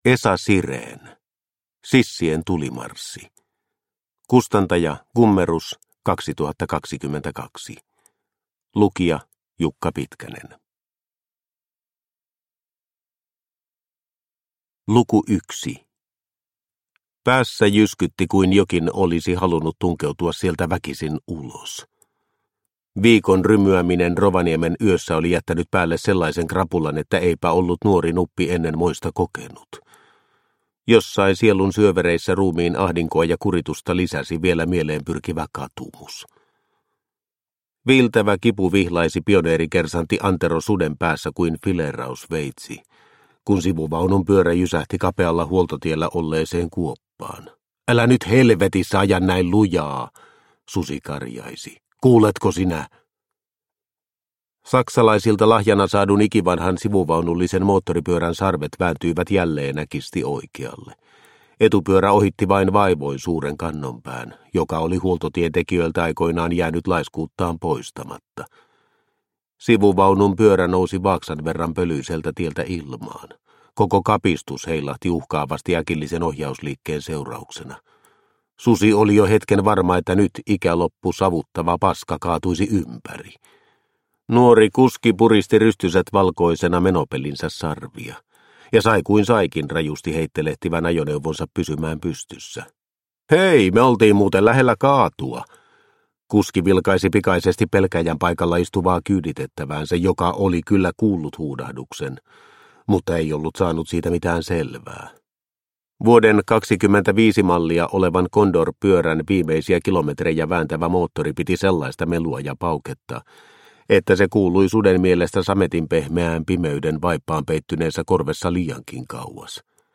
Sissien tulimarssi – Ljudbok – Laddas ner